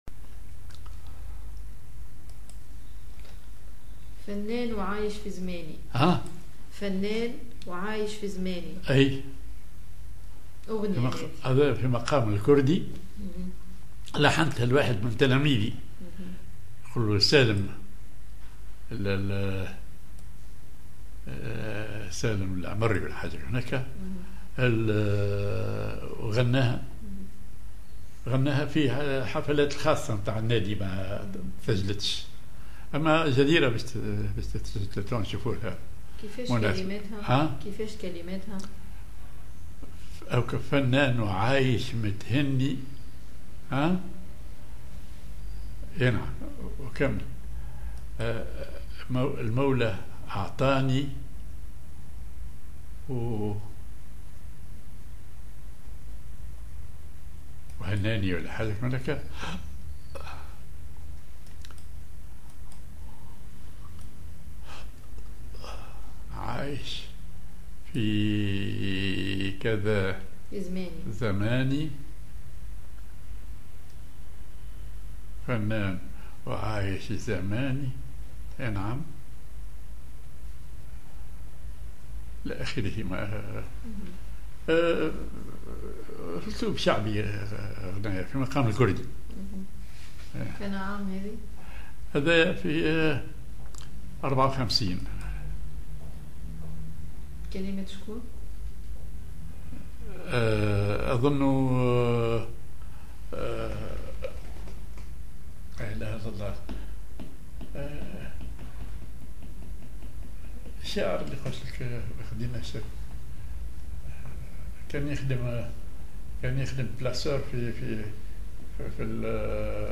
Maqam ar كردي
genre أغنية